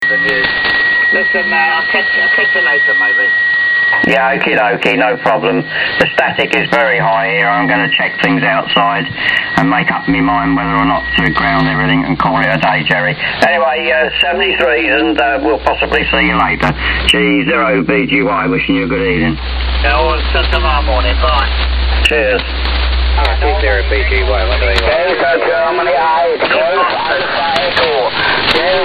7. How does the WINRADIO sound in SSB
Please find a recording below from LSB on 40m.
excalibur_ssb_good.mp3